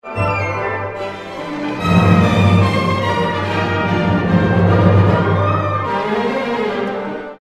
Allegro molto vivace, sol maggiore, C (12/8)
Scherzo-rondò
complementare   bt. 44-51 violini e flauti 16   esempio 16 di partitura (formato PDF)
esempio 16 orchestrale (formato MP3) esempio multimediale (formato formato flash)